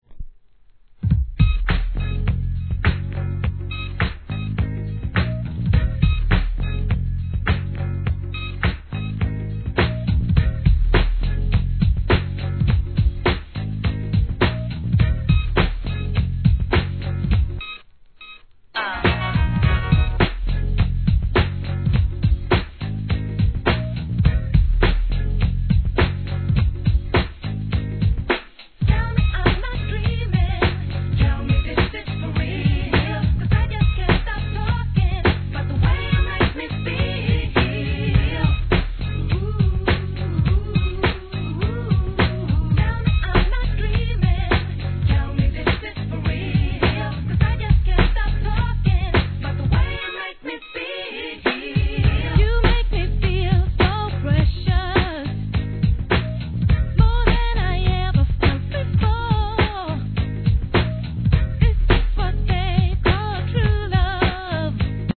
HIP HOP/R&B
1993年の跳ねたBEATで気持ちよく歌います♪